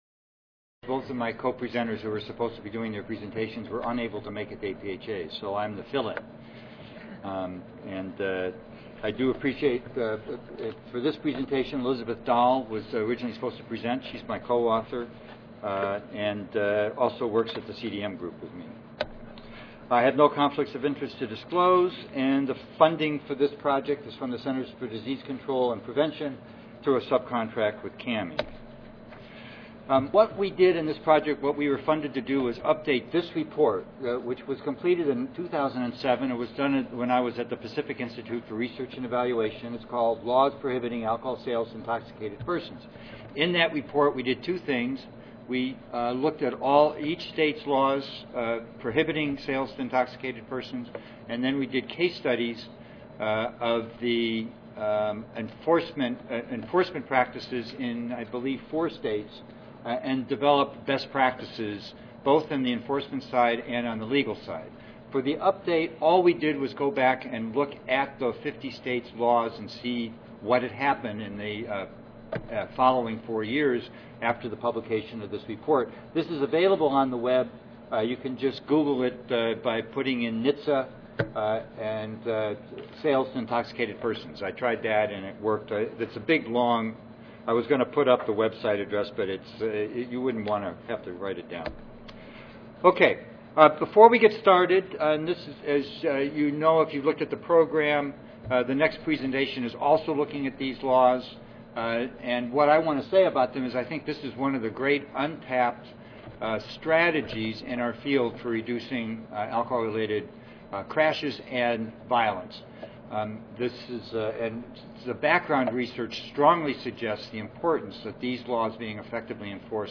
This presentation discusses the most recent research available on SIP laws in the 50 States and the District of Columbia, as of January 2011 and highlights changes that have been made to SIP laws since 2007. It also presents five legal, research-based best practices for SIP regulation and enforcement and identifies the extent to which the States comply with those best practices.